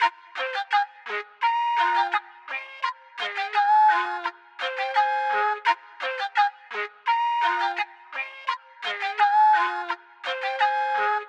长笛燕麦170
标签： 170 bpm Trap Loops Flute Loops 1.90 MB wav Key : Fm Cubase
声道立体声